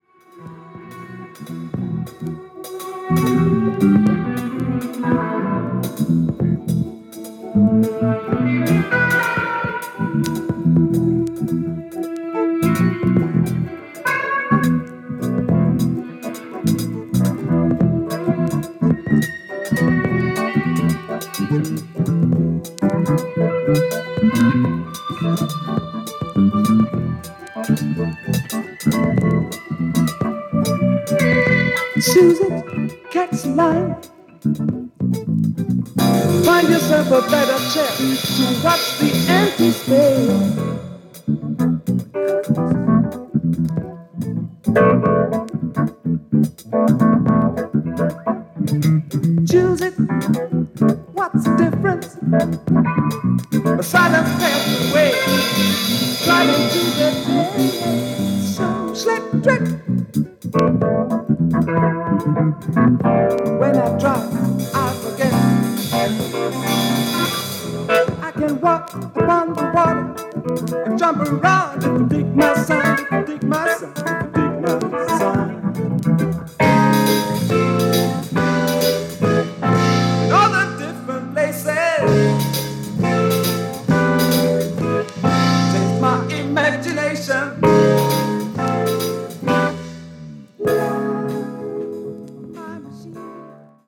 ジャーマン・ジャズ・ロック・バンド